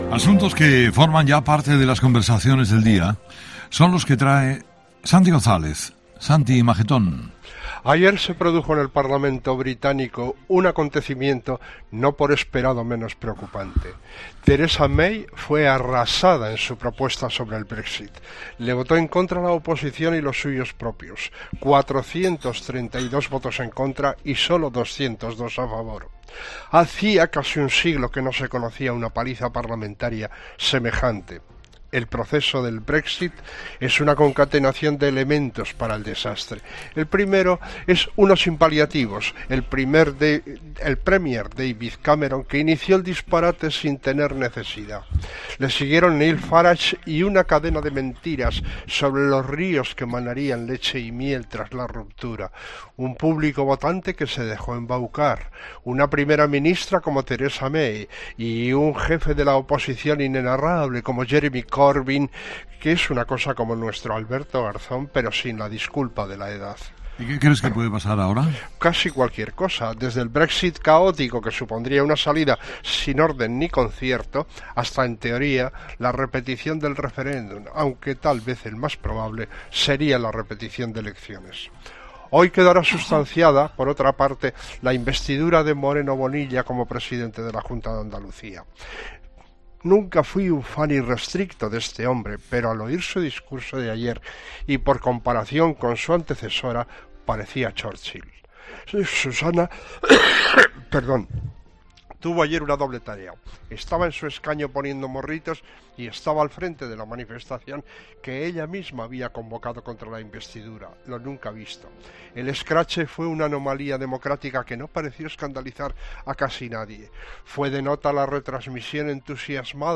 El anállisis de la actualidad de Santi González en 'Herrera en COPE'.